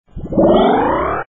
卡通音效铃声二维码下载
ka_tong_yin_xiao525.mp3